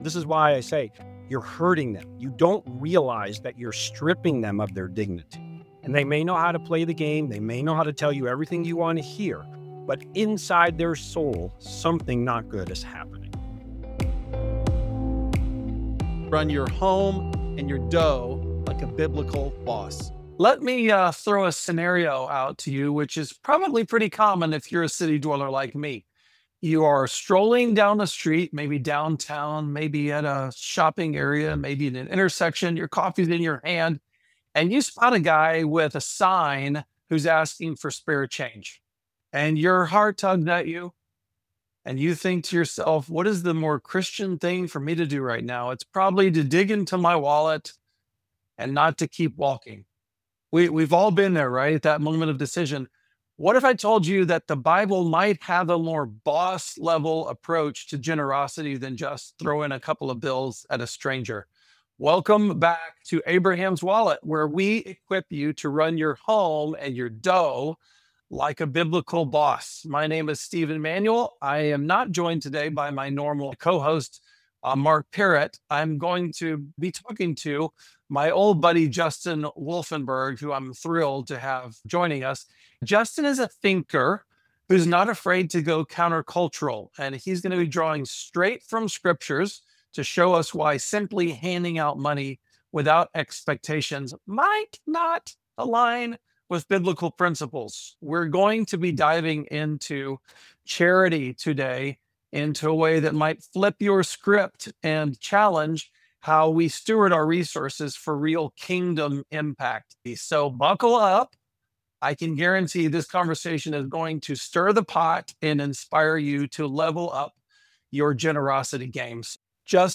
Tune in for a challenging conversation that will flip your view of charity upside down and give you a clearer vision for Kingdom-centered generosity.